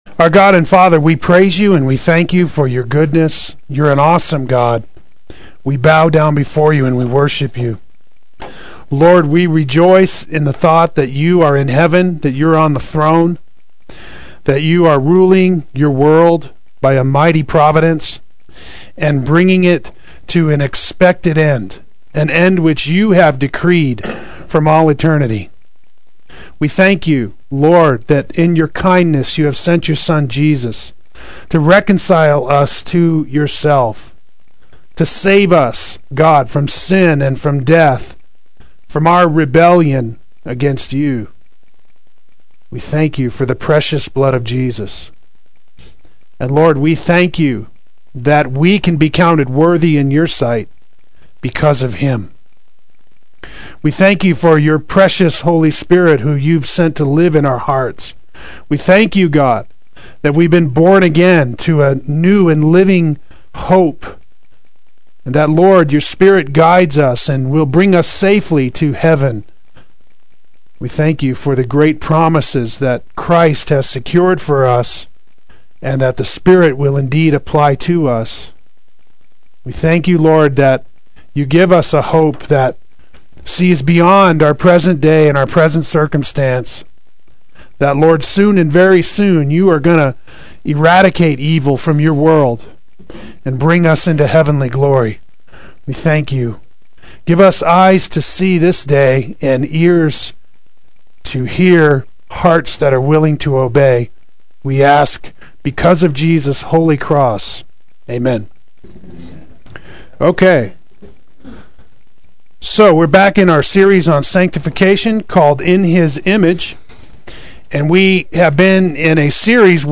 Play Sermon Get HCF Teaching Automatically.
Faith and Virtue Adult Sunday School